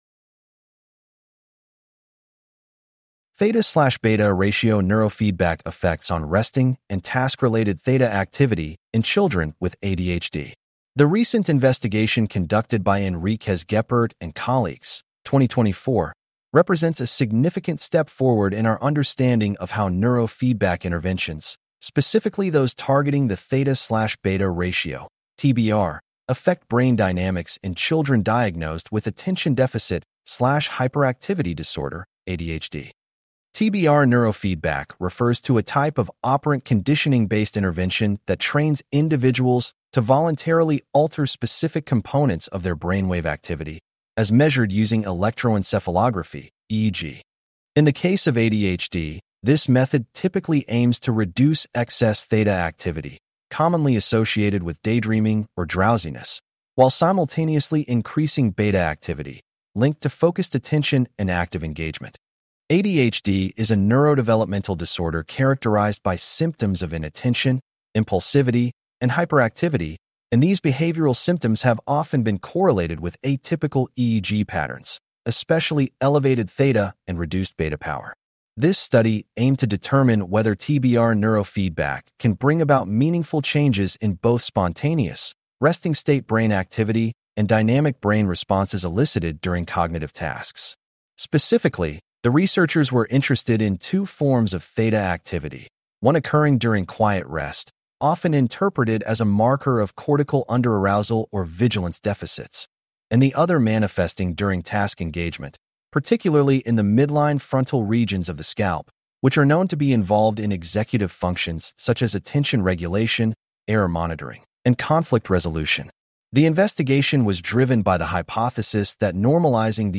CLICK TO HEAR THIS POST NARRATED What Is The Takeaway?